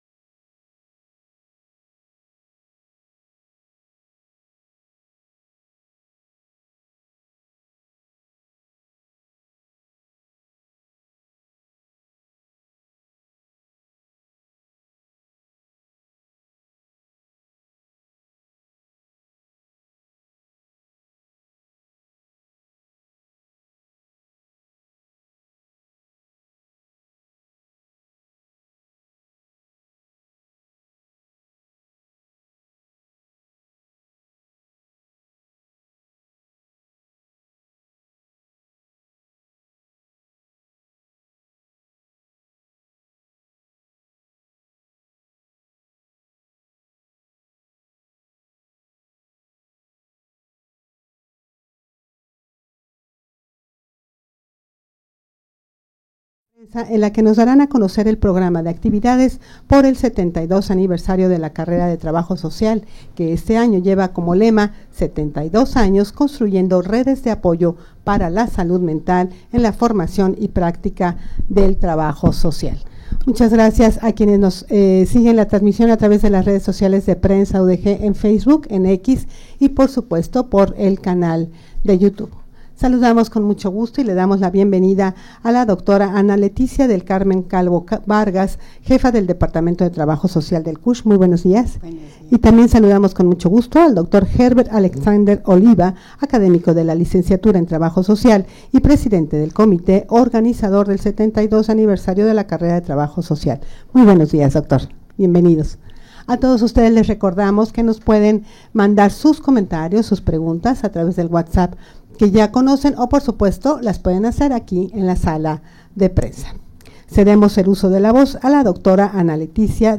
Audio de la Rueda de Prensa
rueda-de-prensa-para-dar-a-conocer-el-programa-de-actividades-por-el-72-aniversario-de-la-carrera-de-trabajo-social.mp3